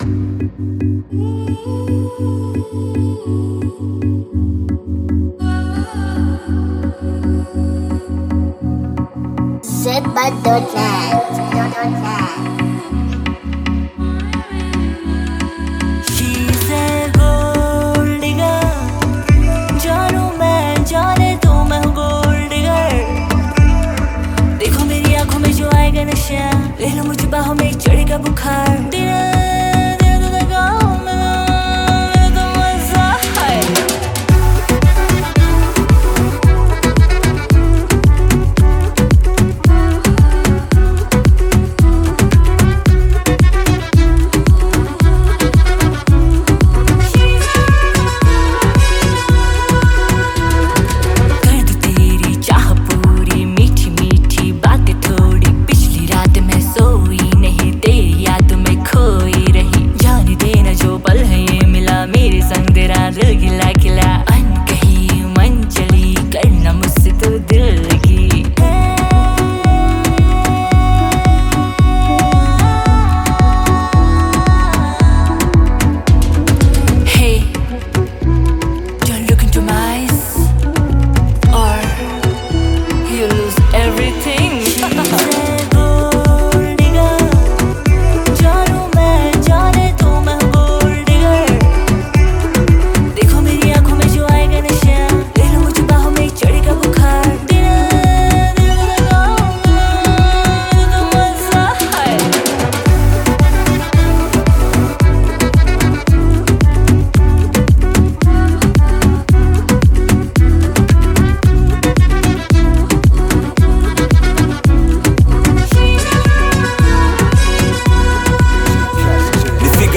Genre: Afrobeats